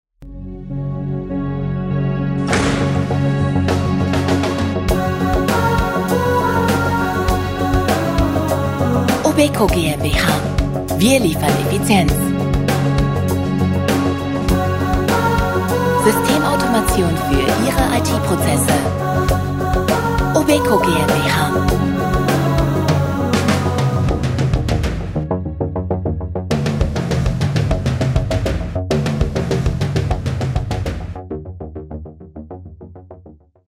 Firmenhymne mit Ansage